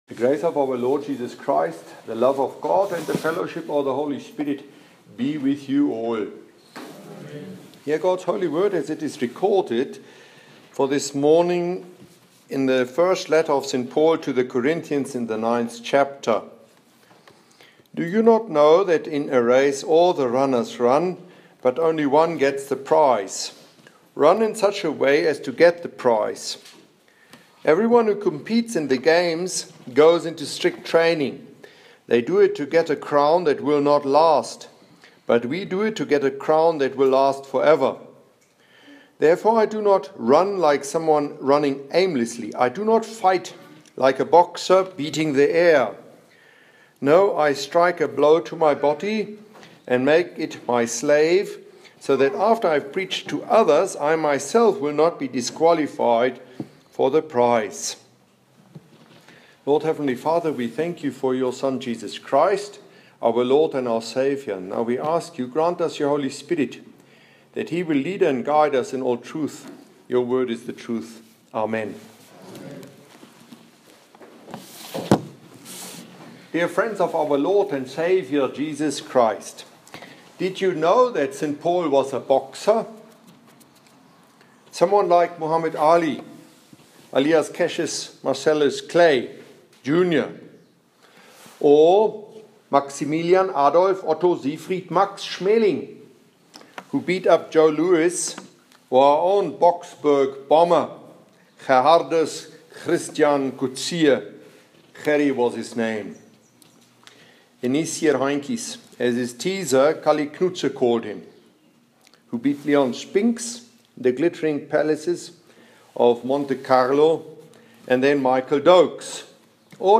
Here's this mornings sermon preached during Matins in the chapel of the Lutheran Theological Seminary called St.Timothy: Matins 1Cor.9,24-27 and the audio-file too: